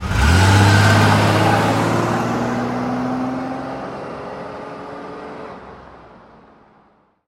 pullaway_out3.ogg